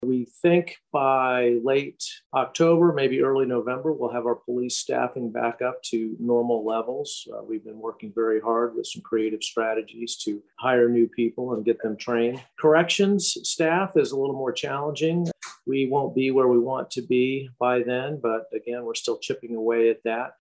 Butler shared his comments during Monday’s virtual intergovernmental luncheon, hosted by Riley County.